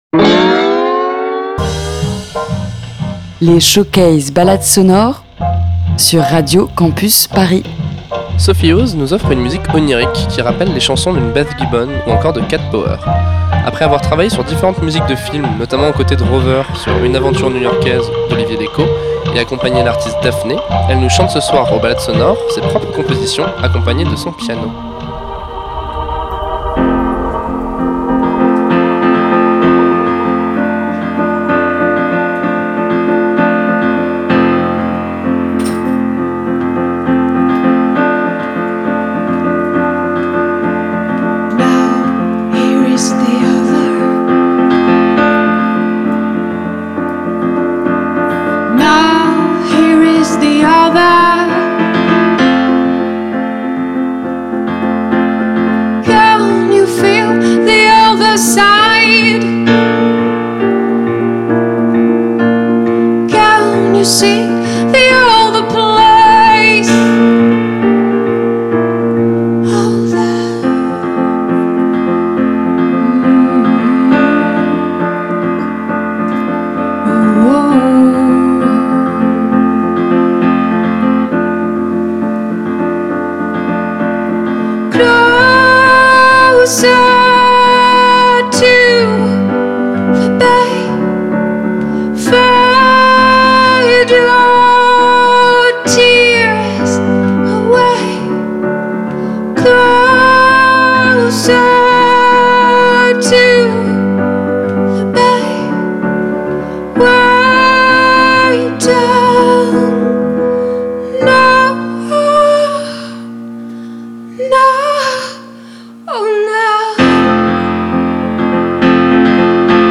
univers musical onirique